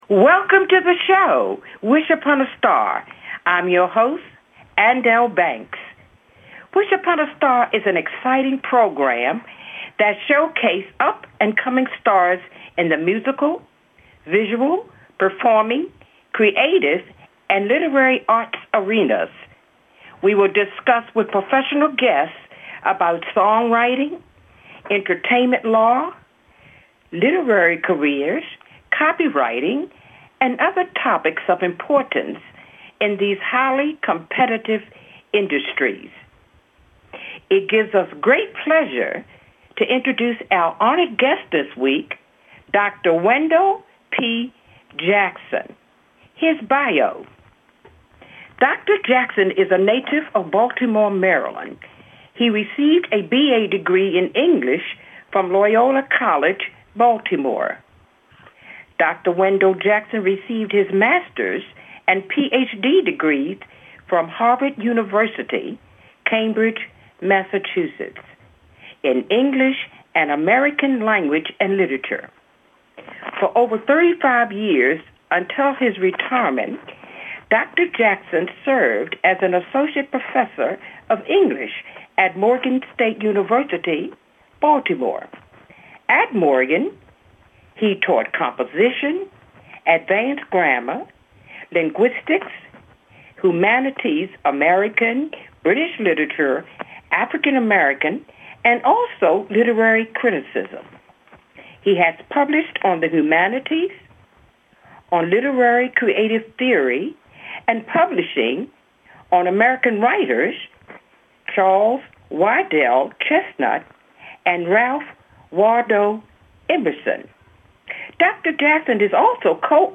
Conversations discussing the challenging elements of visual, musical, literary, painting, and the performing arts. We will interview professional guests in the fields of entertainment law, copyrighting, studio recording, songwriting, publishing, and other topics in these highly creative and challenging fields."